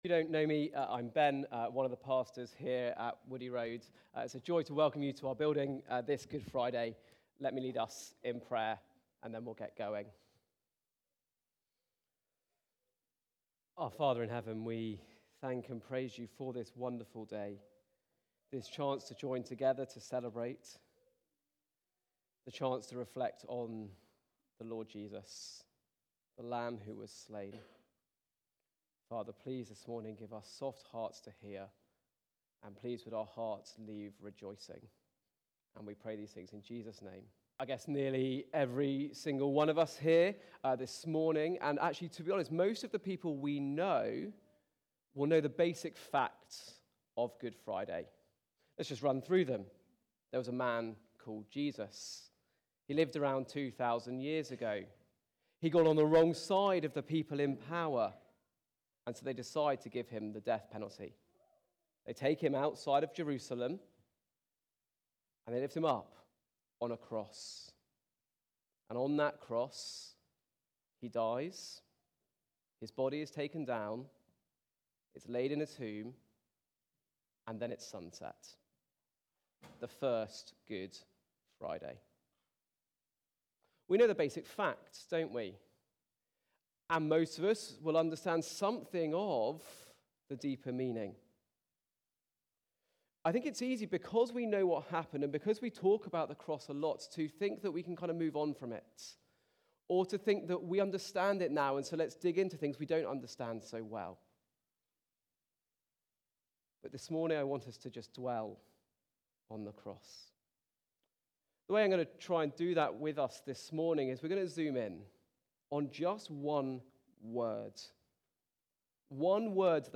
Behold The Lamb (Exodus 12:1-13) from the series Easter 2025. Recorded at Woodstock Road Baptist Church on 18 April 2025.